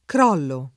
crollo [ kr 0 llo ] s. m.